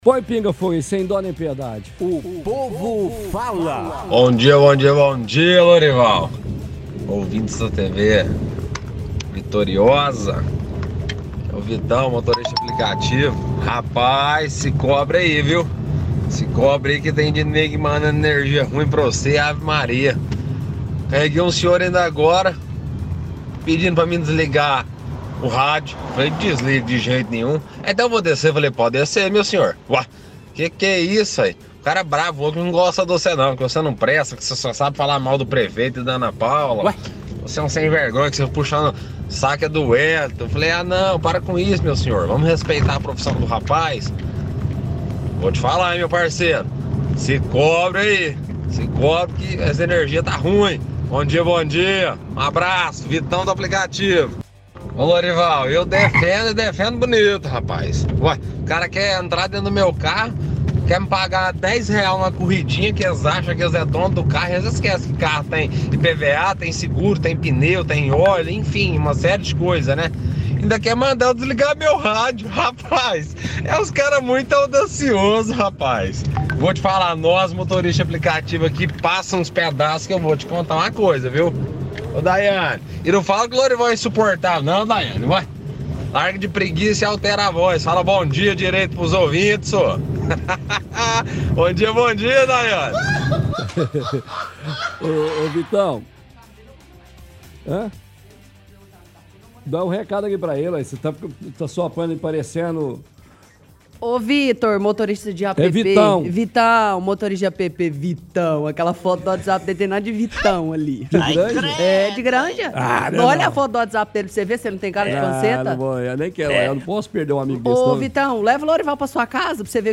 – Retransmite áudio de ouvinte que é motorista de aplicativo e o passageiro pediu para desligar o rádio.